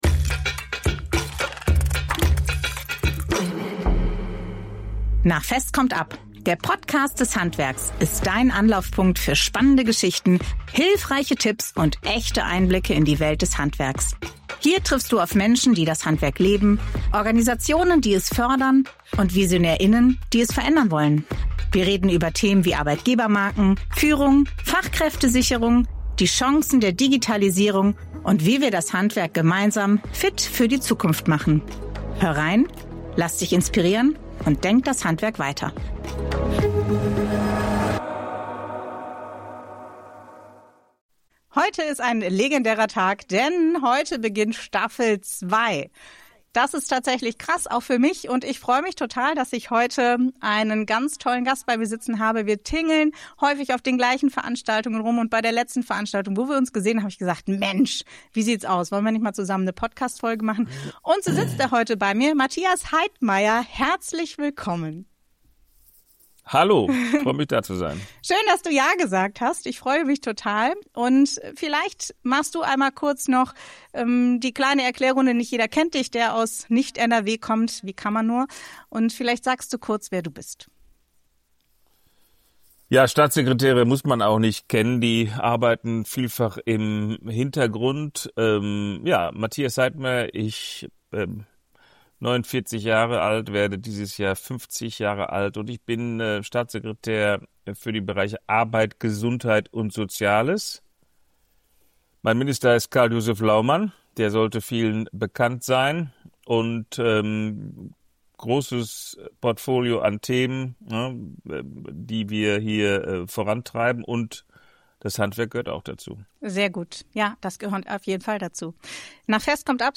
In dieser Folge von „Nach fest kommt ab“ spreche ich mit Matthias Heidmeier, Staatssekretär für Arbeit, Gesundheit und Soziales, über die Rolle des Handwerks in Politik und Gesellschaft.
Matthias_Heidmeier_-_ganze_Folge_mit_Speaking_Jingle.mp3